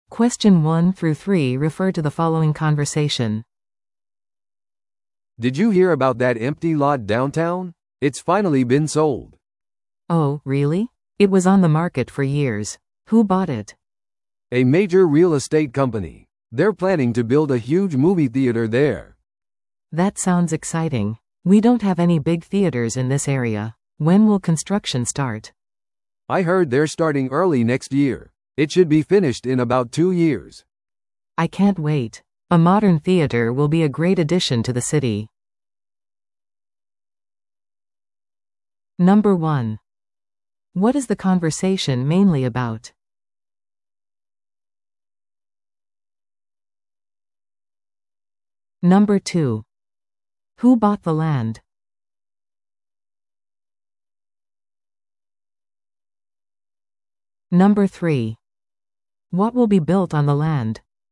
No.1. What is the conversation mainly about?